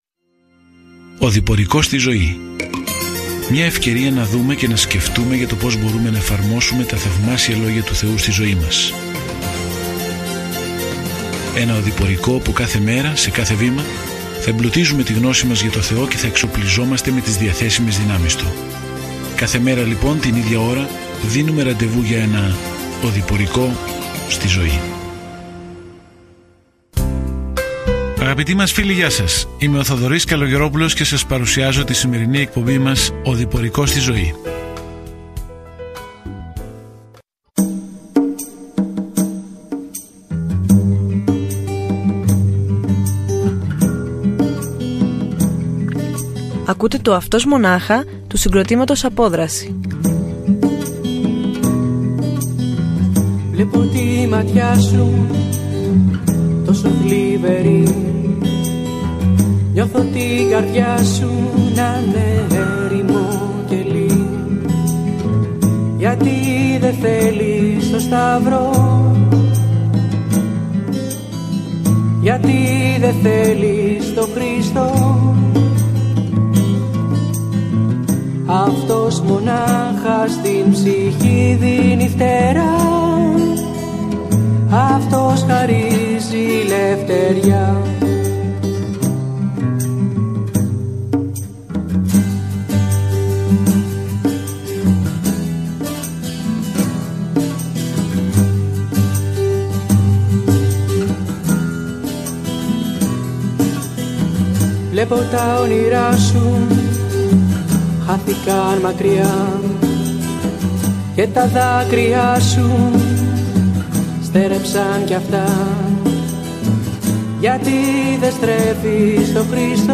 Κείμενο ΙΩΒ 8:8-22 ΙΩΒ 9 Ημέρα 7 Έναρξη αυτού του σχεδίου Ημέρα 9 Σχετικά με αυτό το σχέδιο Σε αυτό το δράμα του ουρανού και της γης, συναντάμε τον Ιώβ, έναν καλό άνθρωπο, στον οποίο ο Θεός επέτρεψε στον Σατανά να επιτεθεί. όλοι έχουν τόσες πολλές ερωτήσεις σχετικά με το γιατί συμβαίνουν άσχημα πράγματα. Καθημερινά ταξιδεύετε στον Ιώβ καθώς ακούτε την ηχητική μελέτη και διαβάζετε επιλεγμένους στίχους από τον λόγο του Θεού.